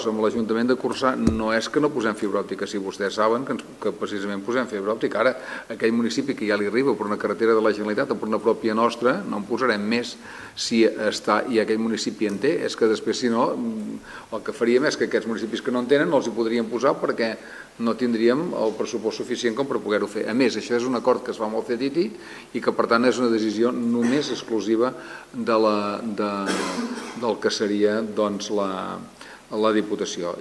Una de les al·legacions del consistori era que el projecte inclogués la instal·lació de fibra òptica, però la Diputació considera que no és necessari perquè Corçà ja en disposa. Són declaracions del president de la institució, Miquel Noguer.